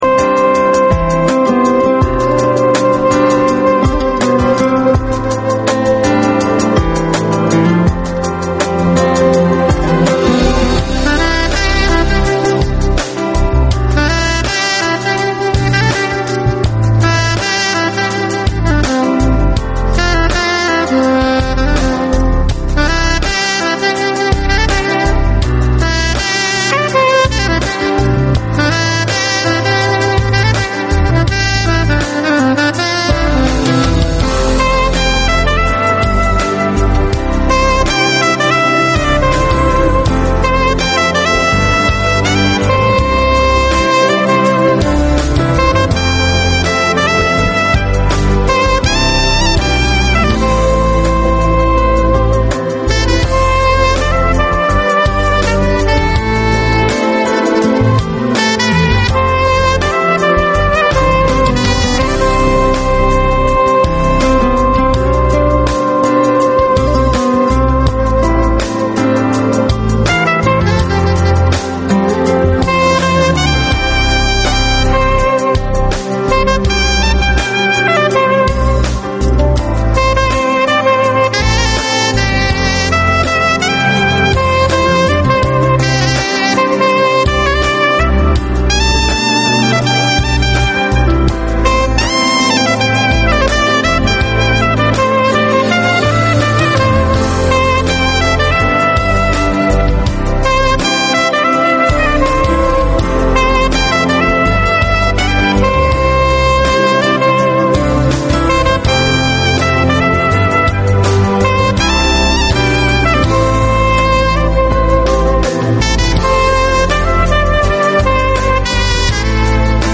Oxu zalı - Əkrəm Əylisli ilə canlı söhbət